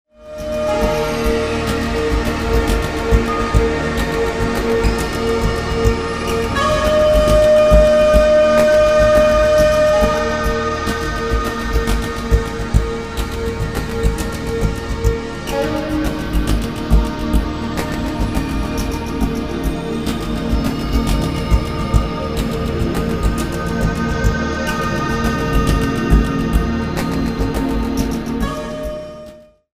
A Modern Dance Choreography CD
18 Instrumental Compositions  /  various orchestrated moods.